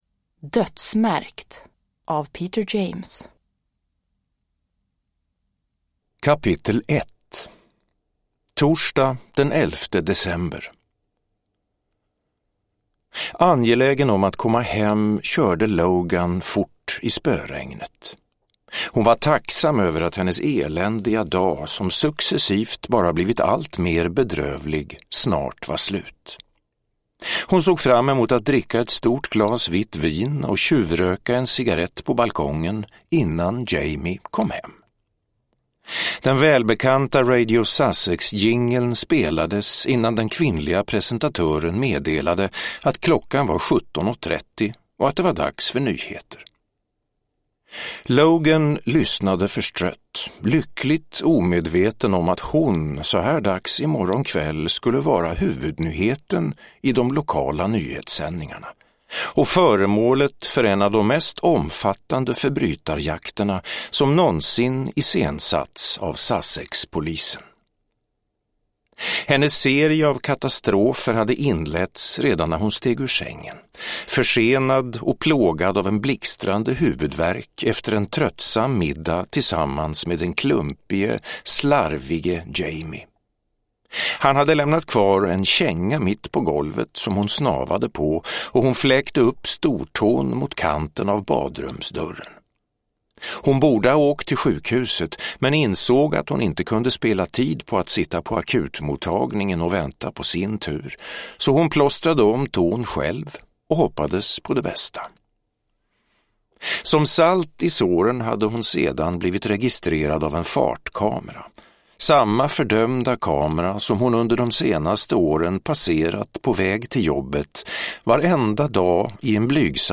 Dödsmärkt – Ljudbok – Laddas ner